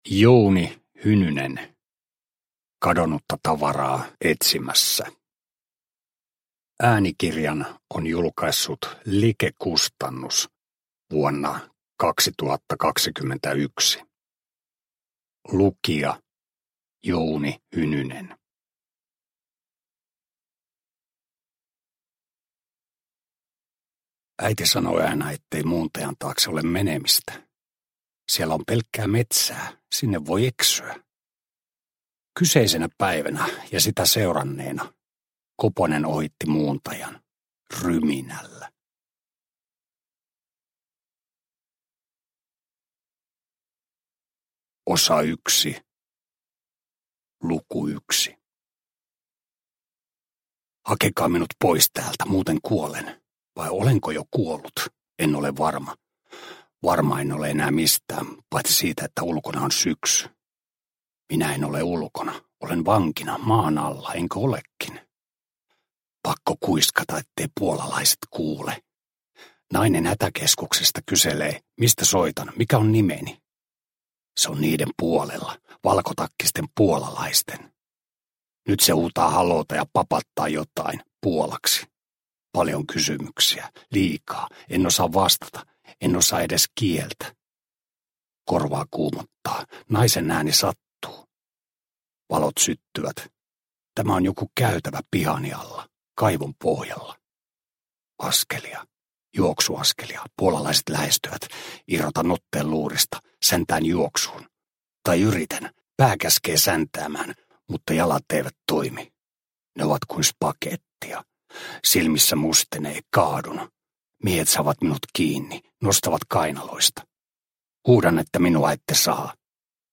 Kadonnutta tavaraa etsimässä – Ljudbok – Laddas ner
Uppläsare: Jouni Hynynen